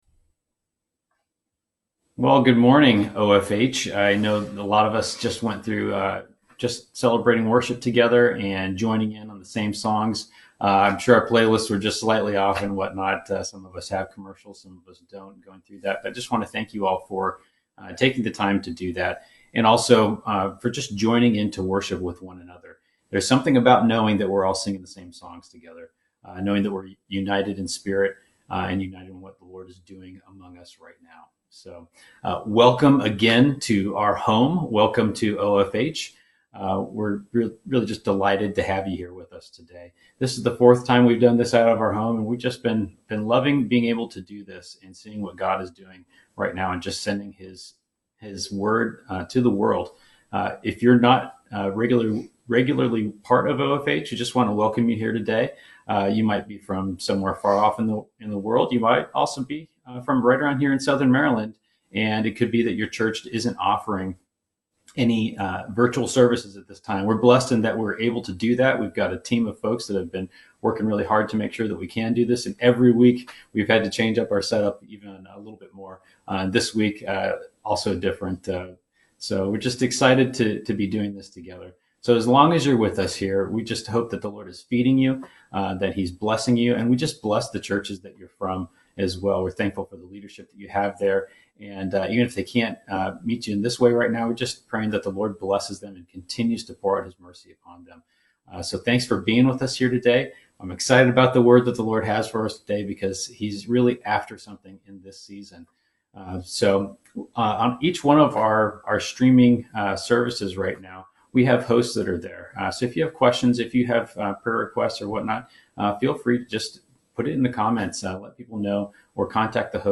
(We are aware that there are some interruptions in this video stream.